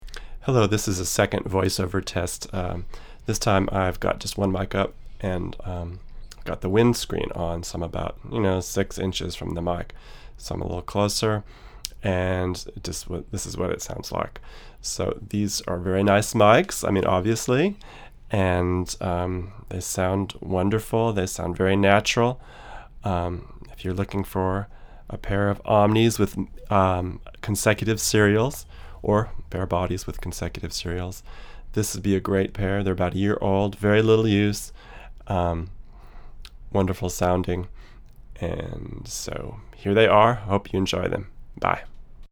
Up for consideration is a stereo pair of Schoeps MK-2 omnidirectional microphone capsules.
Here are sixteen quick, 1-take MP3 sound files showing how the capsules sound using a CMC 6 body (see other ongoing auction) through a Presonus ADL 600 preamp into a Rosetta 200 A/D converter. No EQ or effects.
VOICE OVER: